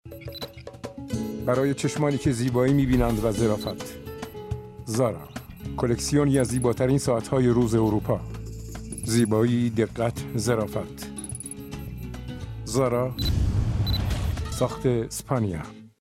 نمونه کار دوبله خسرو خسروشاهی